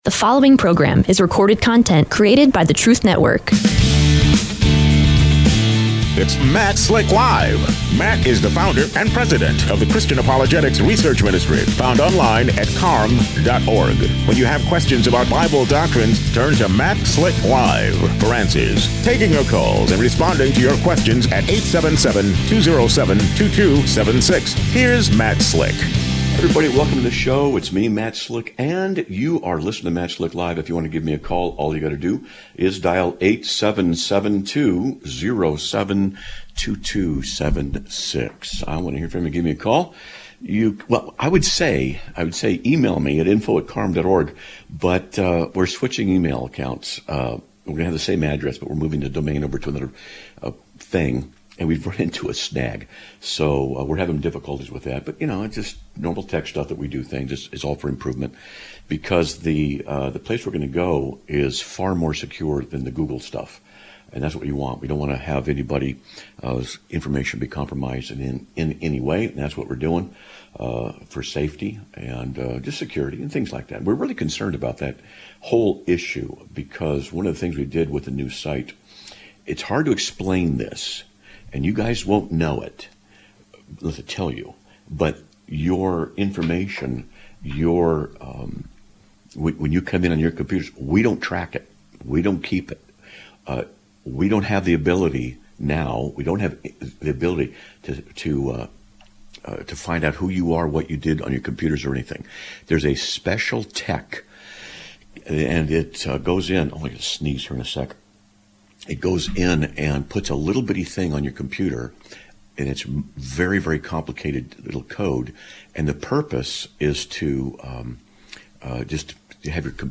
Live Broadcast of 01/07/2026